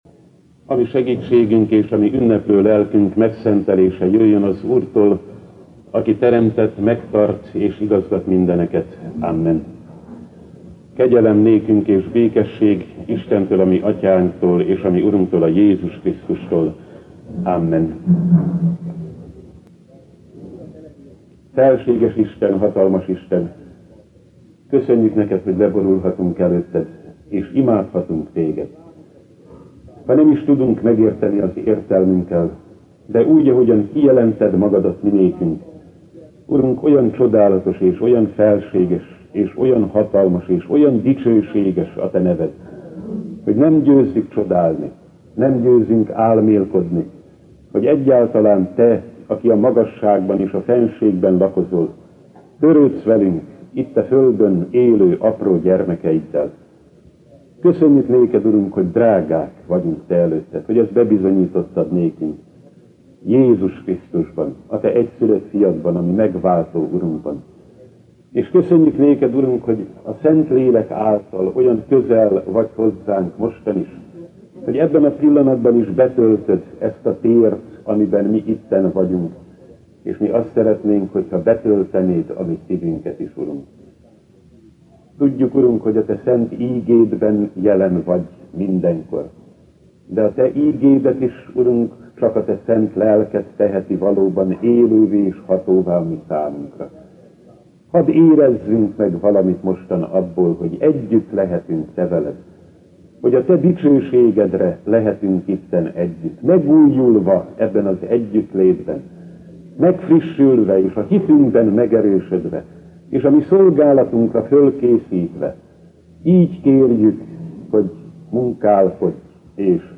SZENTHÁROMSÁG ÜNNEPE - Szent, szent, szent a Seregek Ura, dicsősége betölti az egész földet! (Ézs 6,3) - Technikai okok miatt templomunkban a mai napon hangfelvétel nem készült.